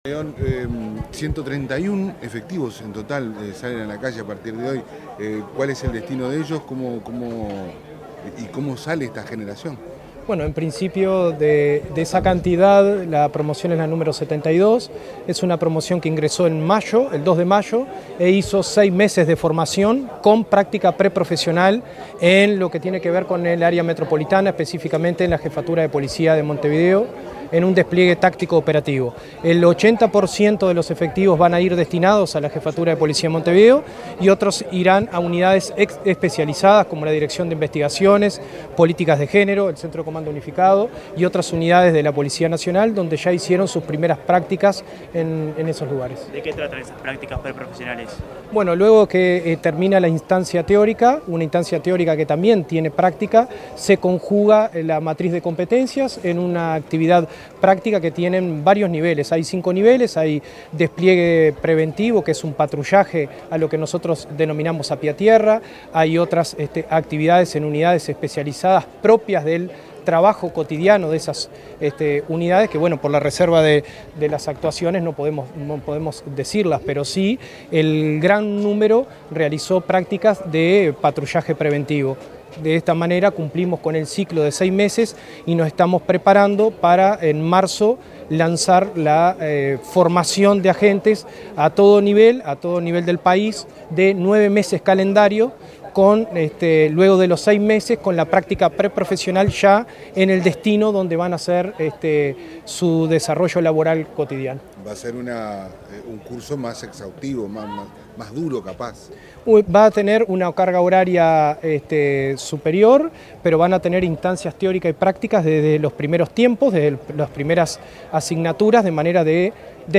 Palabras del director nacional de la Educación Policial, Henry De León
Palabras del director nacional de la Educación Policial, Henry De León 11/11/2025 Compartir Facebook X Copiar enlace WhatsApp LinkedIn Tras la ceremonia de egreso de la Promoción LXXII de la Escuela Policial de la Escala Básica, denominada Honor, Disciplina y Denuedo, se expresó el director nacional de la Educación Policial, Henry De León.